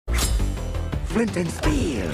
Flint And Steel Meme Pmpgxoo